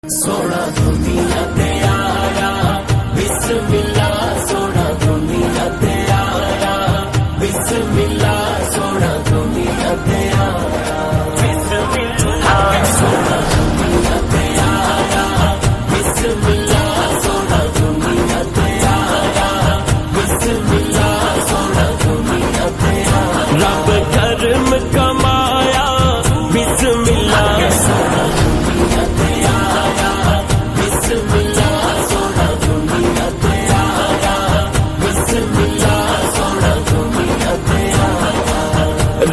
Naat Status